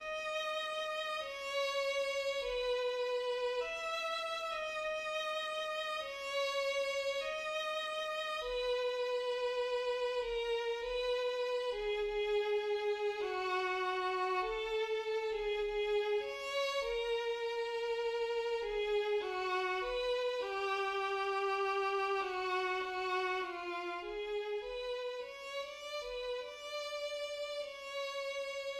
Larghetto 3/4 B major
The lyrical opening theme, Excerpt 9, is supposed to have originated from Excerpts 1 and 4.[18]
Excerpt 9 (violin)